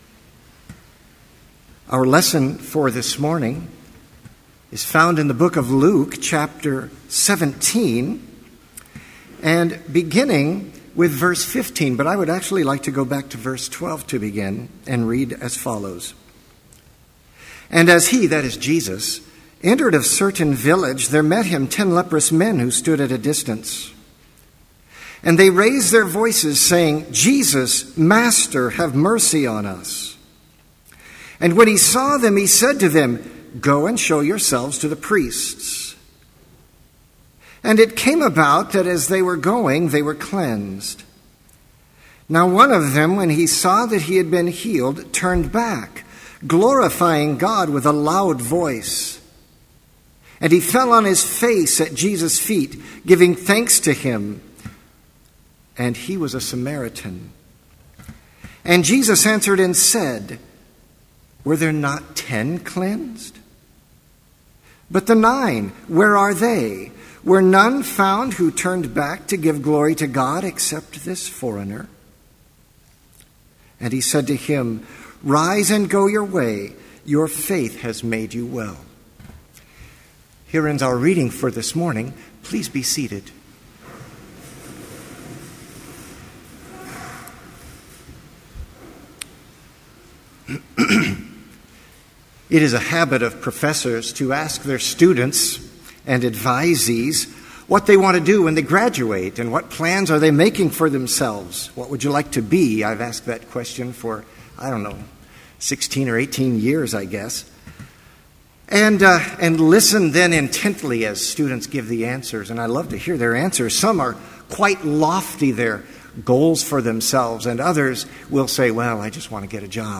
Complete service audio for Chapel - September 4, 2013